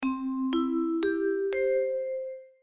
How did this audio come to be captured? Recorded with Online Sequencer